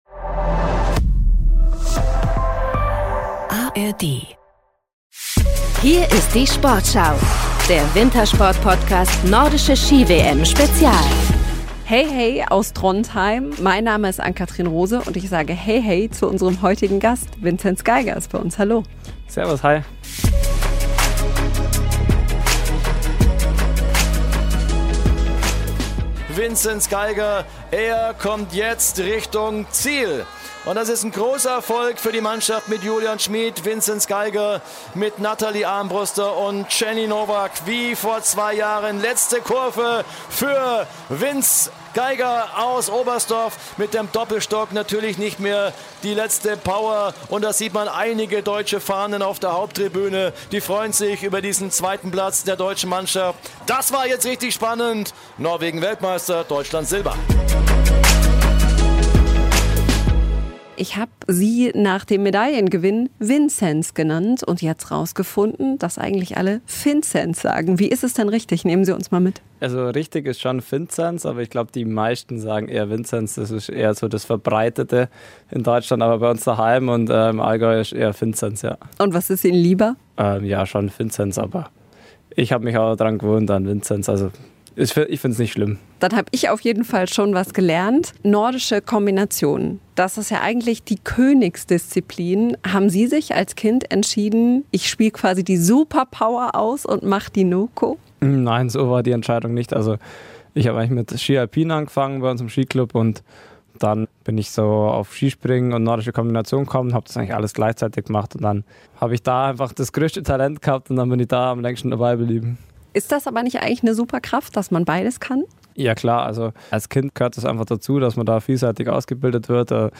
Beschreibung vor 1 Jahr Neues aus Norwegen: Vinzenz Geiger hat in seiner Karriere schon große Erfolge gefeiert - und doch ist die Nordische Ski-WM in Trondheim für den Olympiasieger etwas ganz Besonderes. Im Sportschau Wintersport-Podcast aus dem Teamhotel in der norwegischen WM-Stadt spricht der Kombinierer über Gänsehaut in großen Momenten, verrät wie verrückt die norwegischen Fans sind und wie das Treffen mit der norwegischen Königin Sonja war. Und: Er zieht nach der ersten WM-Woche eine Zwischenbilanz, spricht über seine Rolle im Team und seine Superkraft.